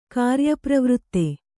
♪ kāryapravřtte